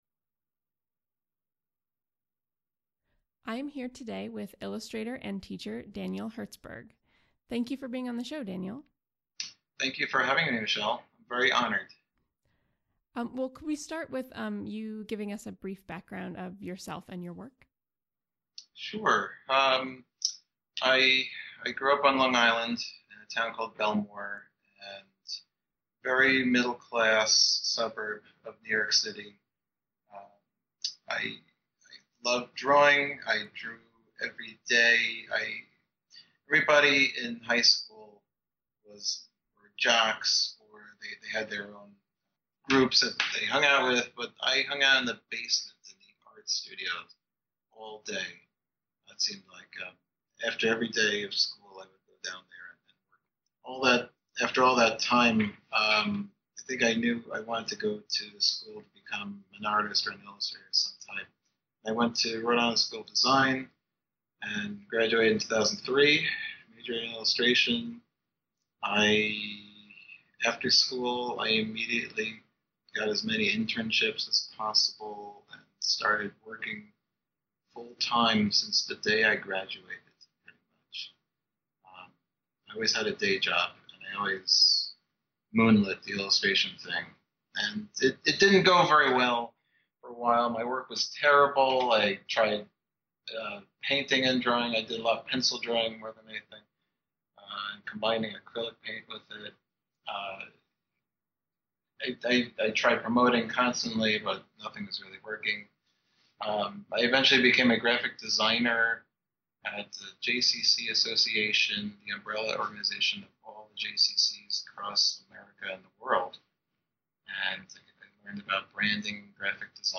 Episode 8 of Creative Playdate features an interview